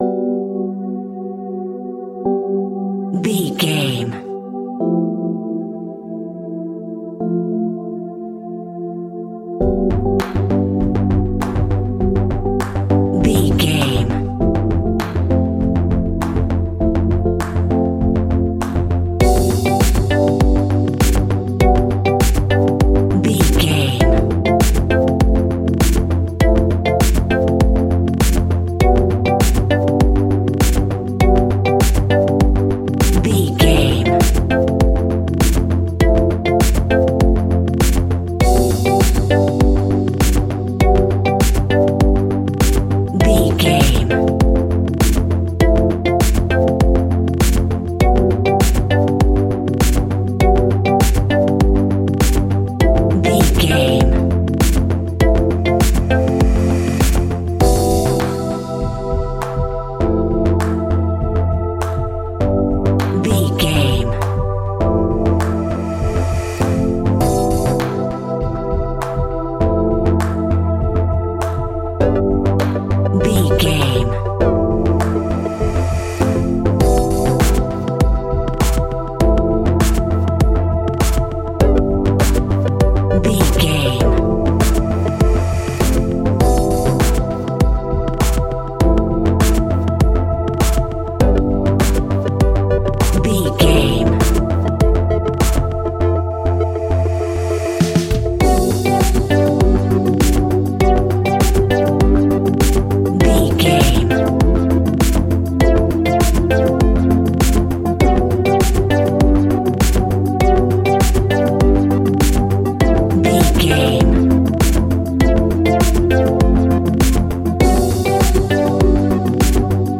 Ionian/Major
ethereal
dreamy
cheerful/happy
synthesiser
drum machine
electronica
synth leads
synth bass
synth pad
robotic